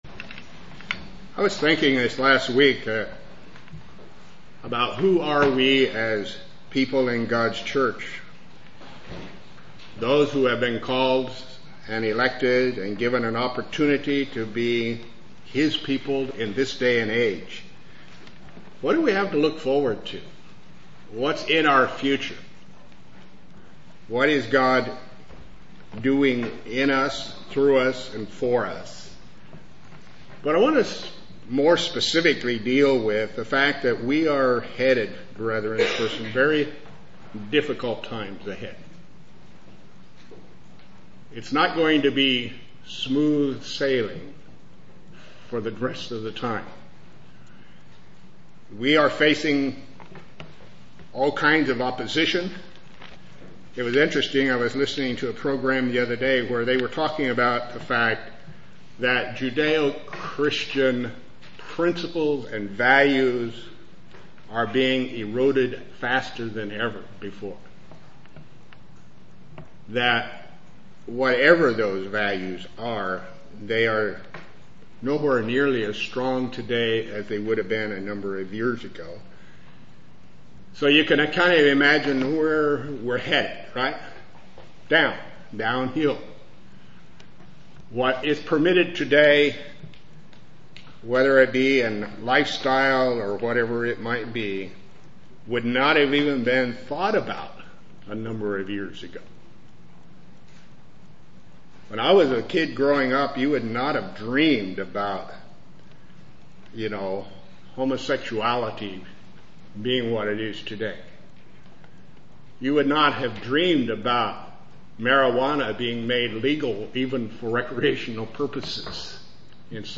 Given in Lewistown, PA
UCG Sermon Studying the bible?